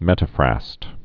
(mĕtə-frăst)